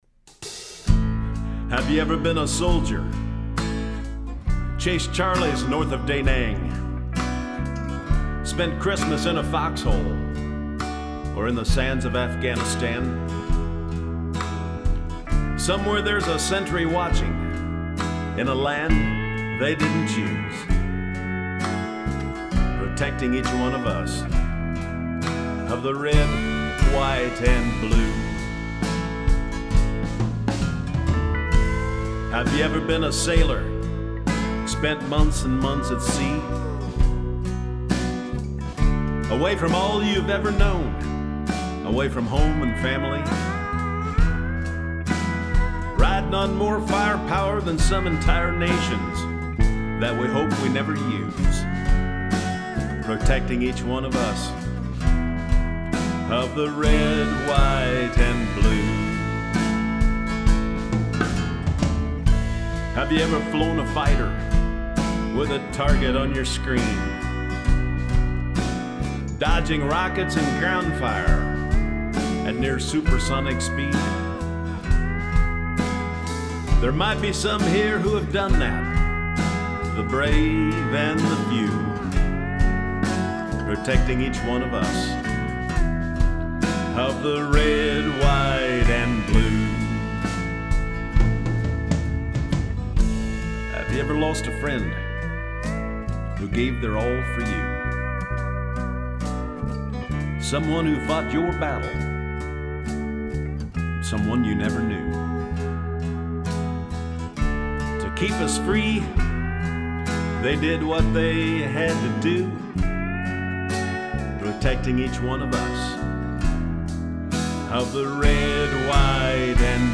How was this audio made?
• Close and room mics